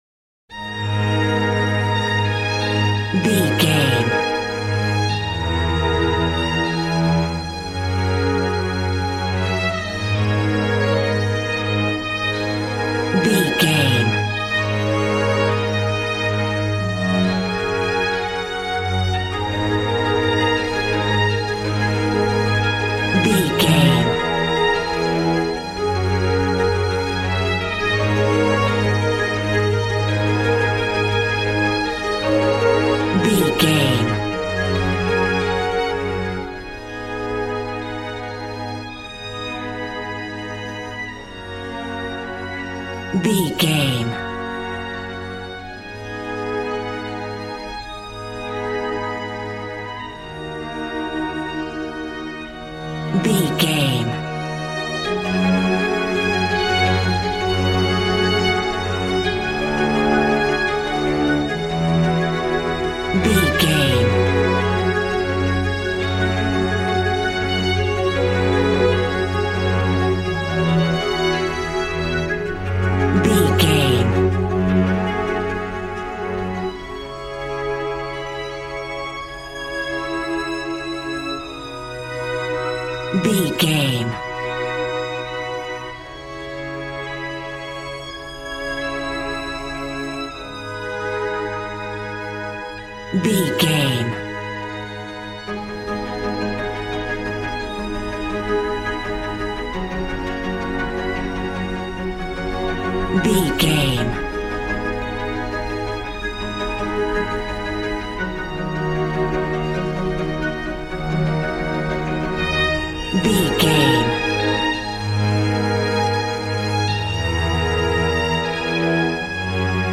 Beautiful stunning solo string arrangements.
Regal and romantic, a classy piece of classical music.
Aeolian/Minor
A♭
regal
strings
brass